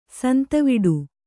♪ santiviḍu